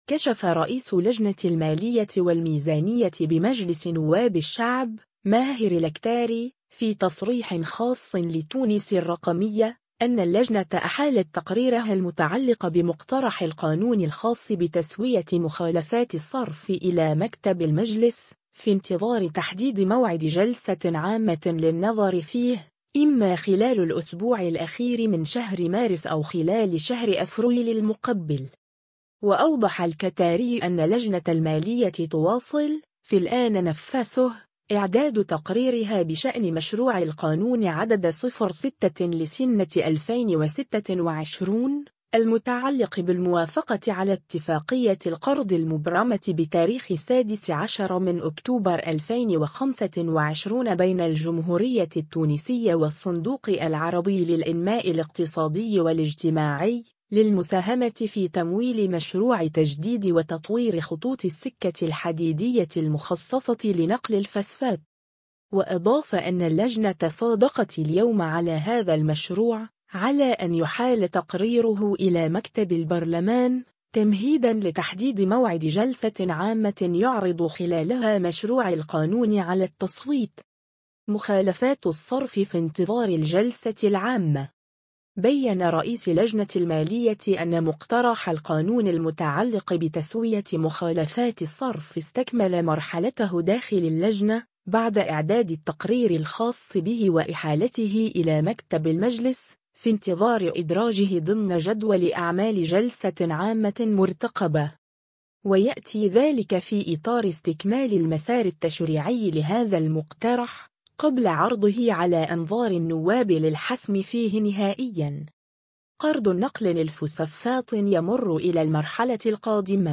كشف رئيس لجنة المالية والميزانية بمجلس نواب الشعب، ماهر الكتاري، في تصريح خاص لـ”تونس الرقمية”، أن اللجنة أحالت تقريرها المتعلق بمقترح القانون الخاص بتسوية مخالفات الصرف إلى مكتب المجلس، في انتظار تحديد موعد جلسة عامة للنظر فيه، إما خلال الأسبوع الأخير من شهر مارس أو خلال شهر أفريل المقبل.